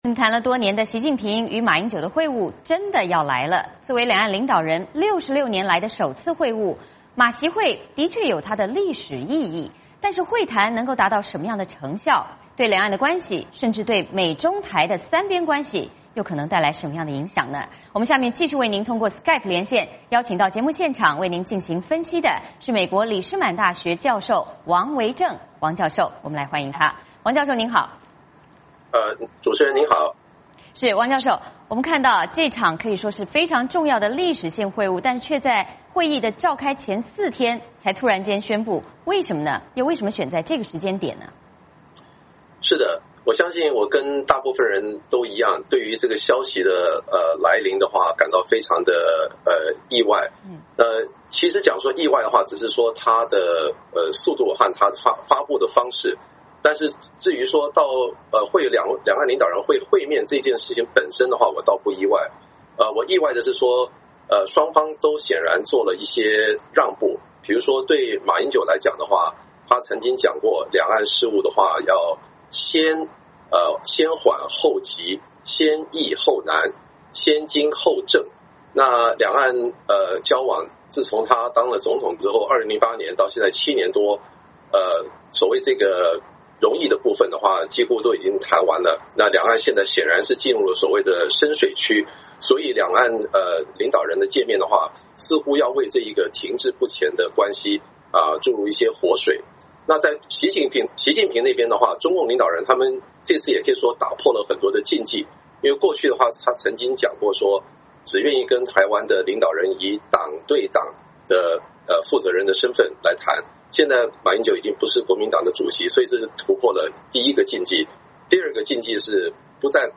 谈了多年的习近平与马英九会晤真的要来了，作为两岸领导人66年来的首次会晤，习马会的确有其历史意义，但是会谈能达到什么成效，对两岸关系，对美中台三边关系可能带来什么样的影响?下面通过SKYPE